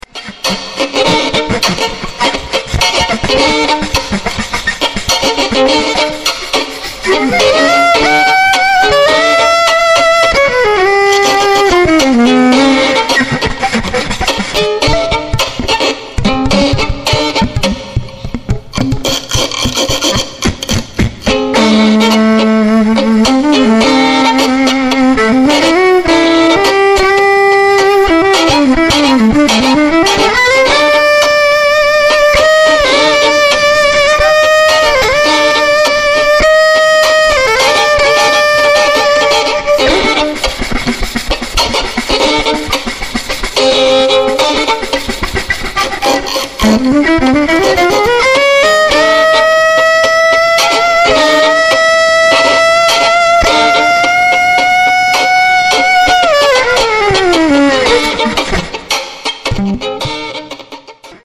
violin, synthesizer
violin
All the tracks are free improvisations
Recorded live, without overdubbing